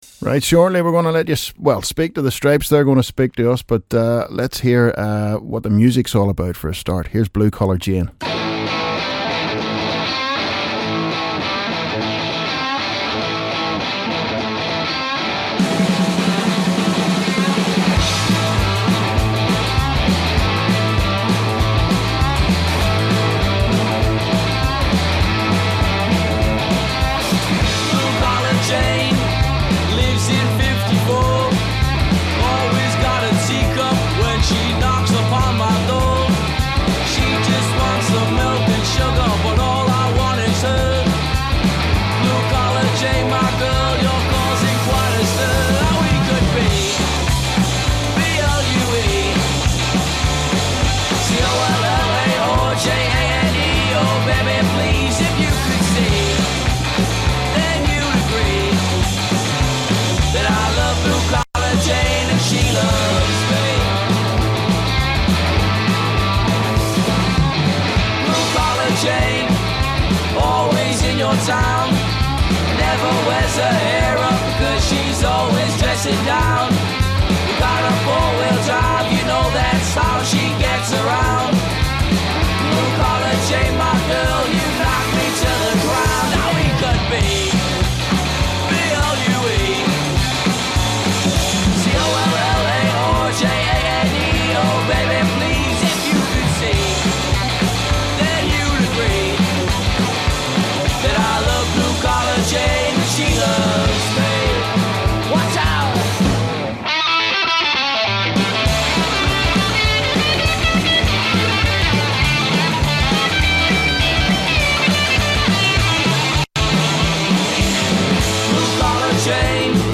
THE STRYPES INTERVIEW